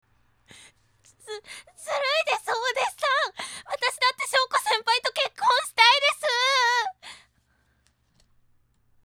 （※クリックするとmp3／素直な感想なので折角だから叫んでみた）